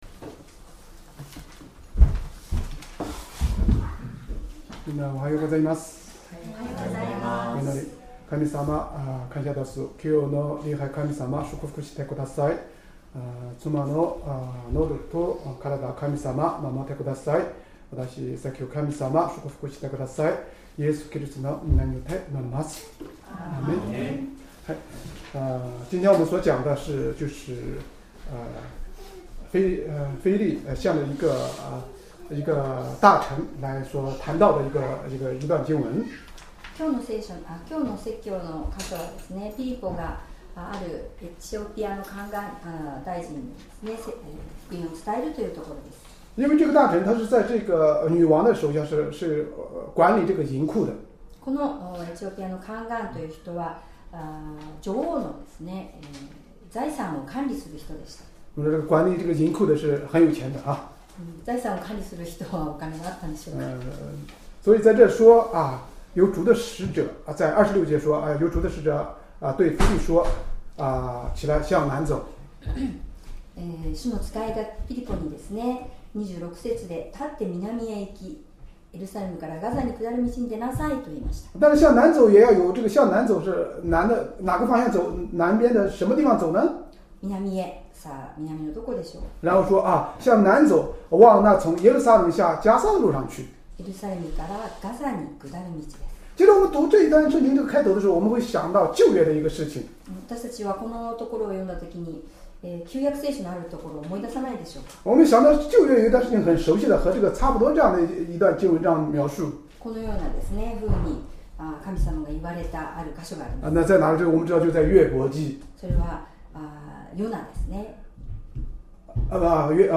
Sermon
2025年5月18日 主日礼拝 説教 「エチオピアの宦官への伝道」 聖書 使徒の働き ８章 26-40節 8:26 さて、主の使いがピリポに言った。